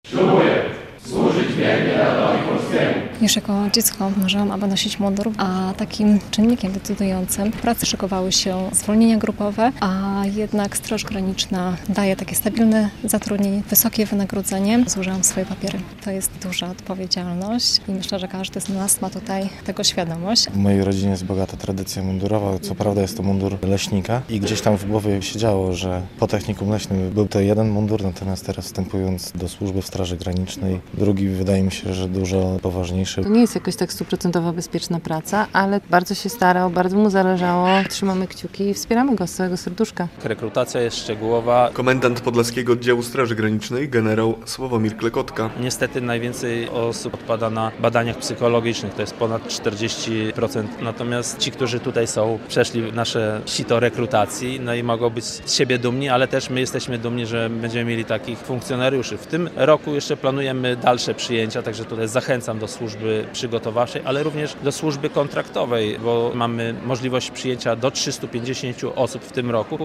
Ślubowanie nowych funkcjonariuszy Straży Granicznej - relacja